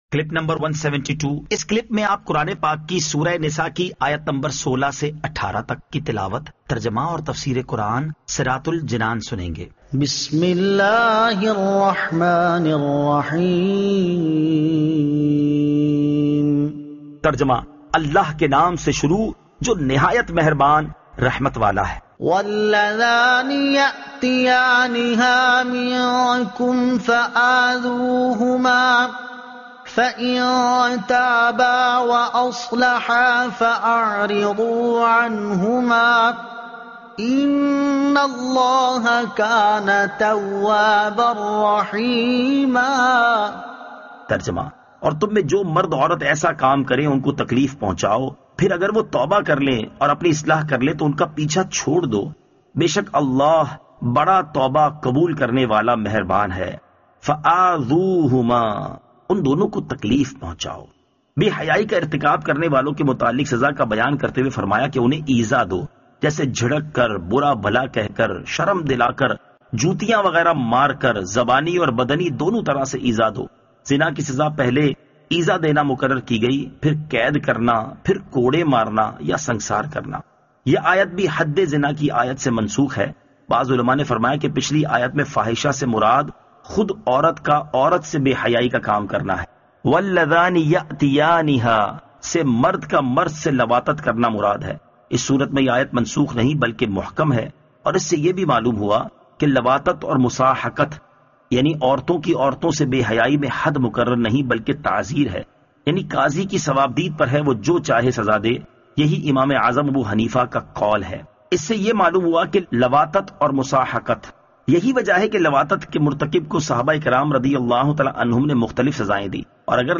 Surah An-Nisa Ayat 16 To 18 Tilawat , Tarjuma , Tafseer